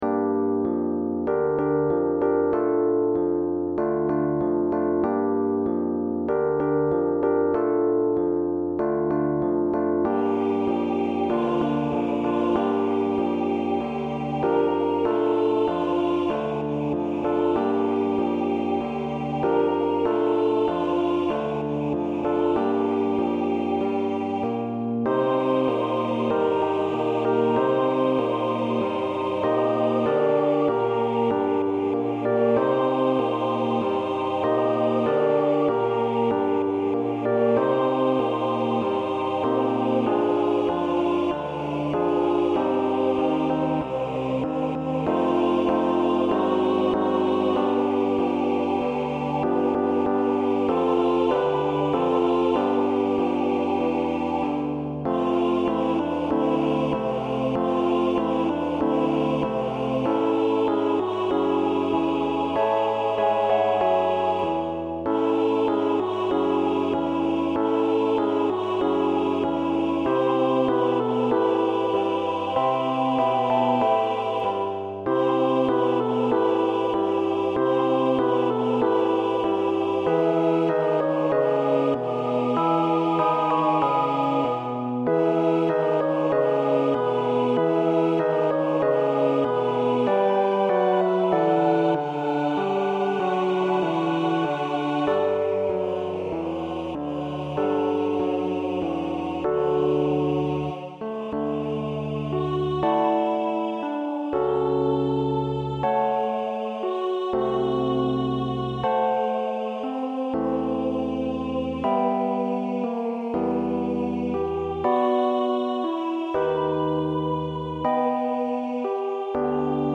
A computer generated sound file is included.
Voicing/Instrumentation: SATB